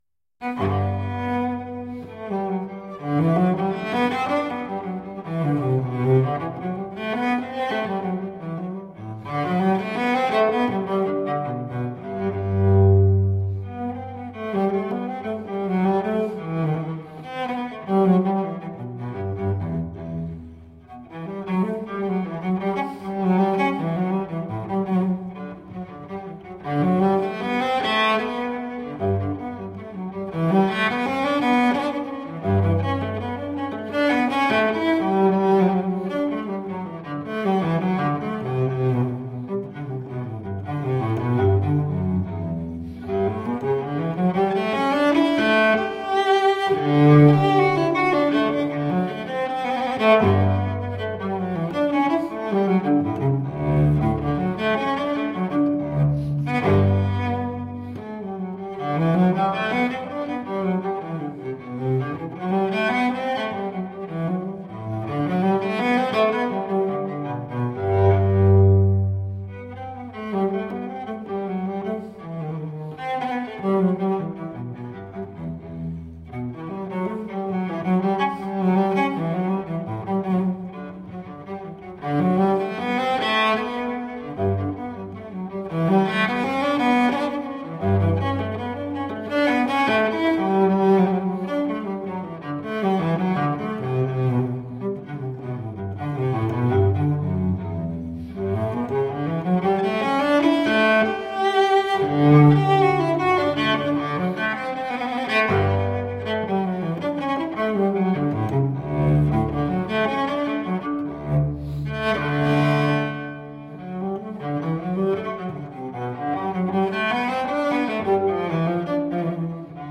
Cellist extraordinaire.